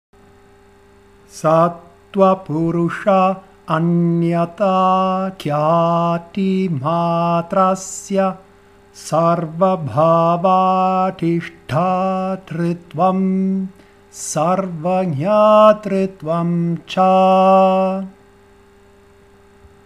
Vibhuti Padah canto vedico